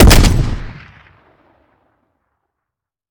Home gmod sound weapons xm109
weap_xmike109_fire_plr_01.ogg